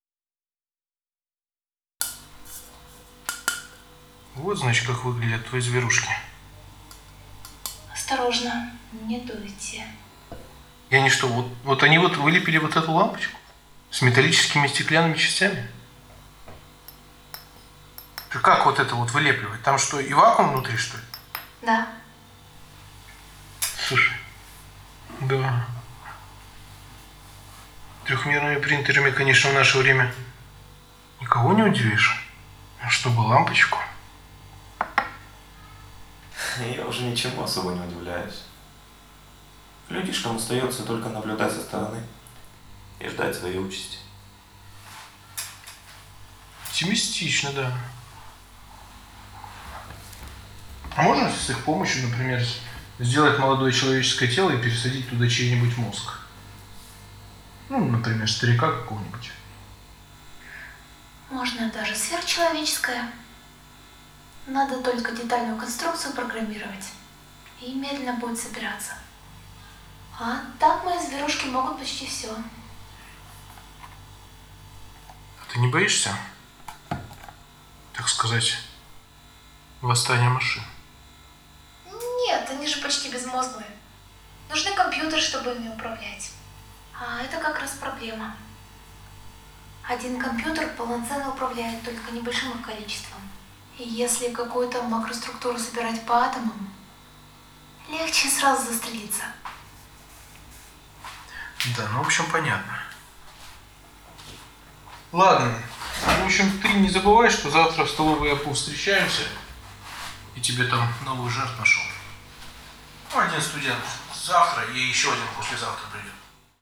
Техно-опера